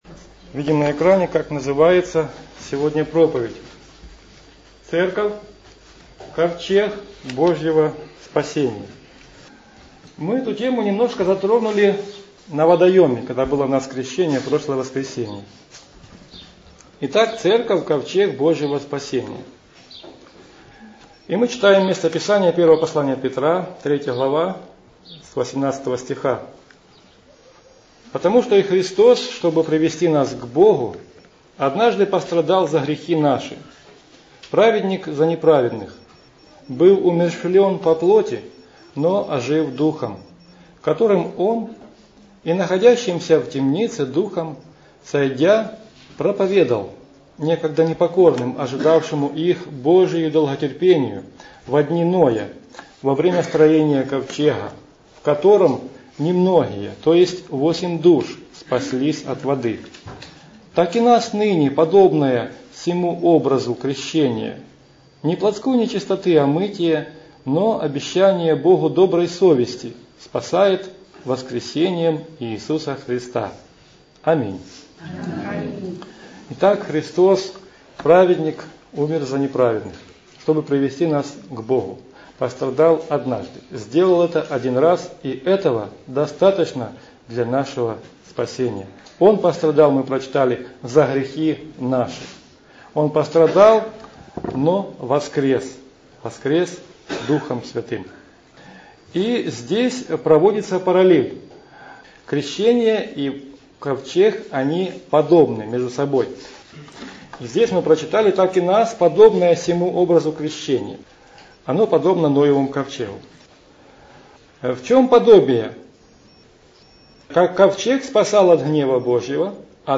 Аудио-проповедь.